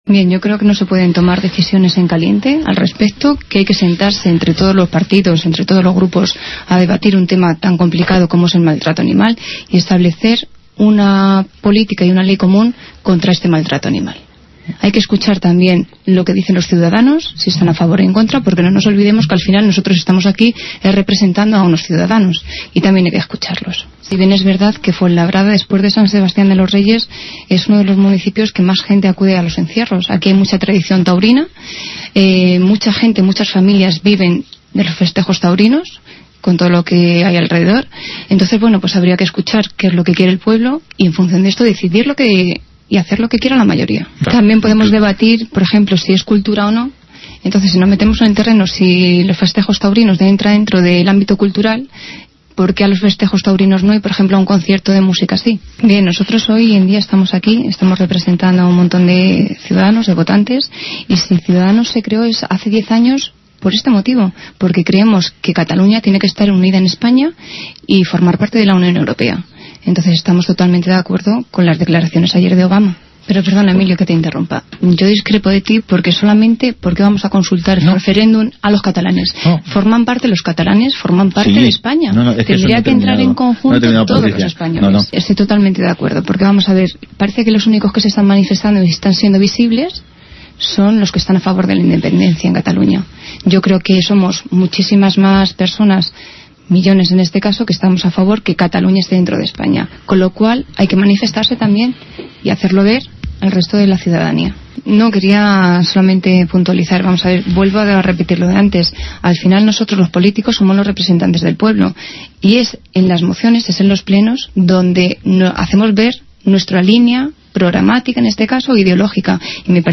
Resumen de nuestra portavoz en la Tertulia de SER Madrid Sur
Patricia de Frutos, portavoz de C’s Fuenlabrada